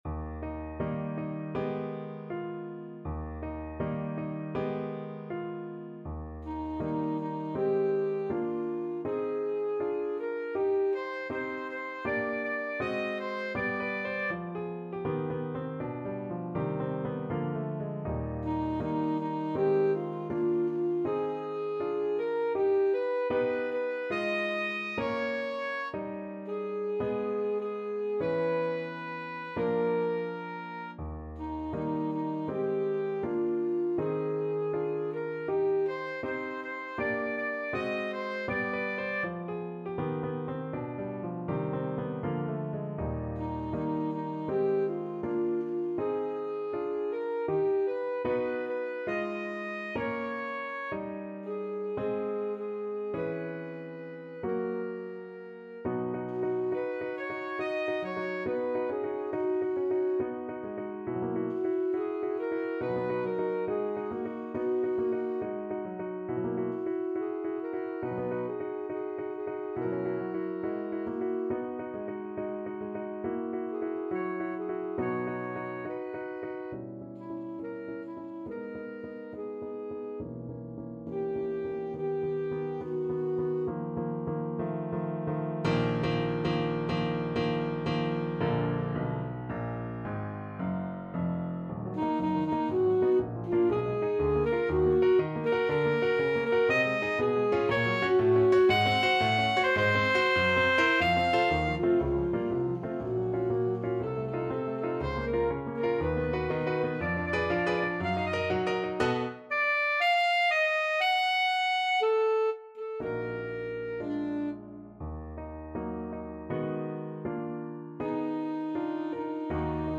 Alto Saxophone
2/4 (View more 2/4 Music)
Moderato =80
Classical (View more Classical Saxophone Music)